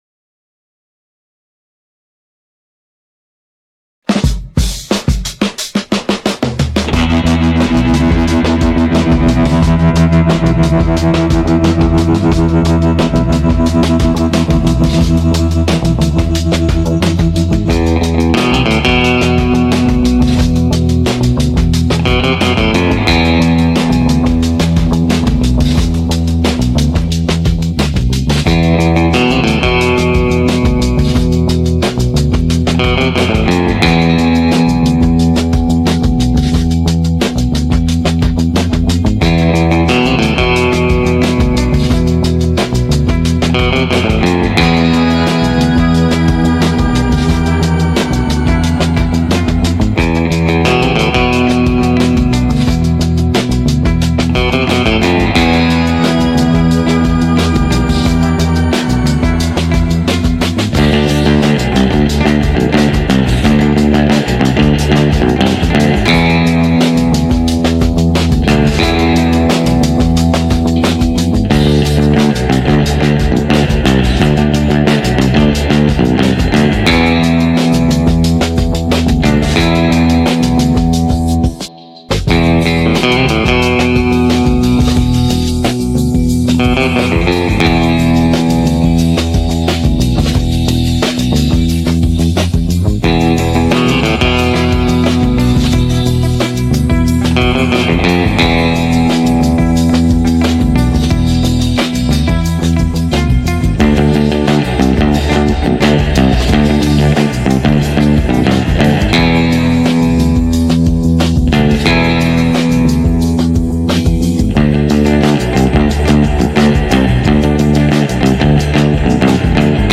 Desert Chill - Baritone guitar tune
This thing has awesome sustain and is very clear sounding
I think this tune would make a great sound track for a movie. very good
I put the surfish pipeline part in there for you.
The formal musical name for that is "Glissando".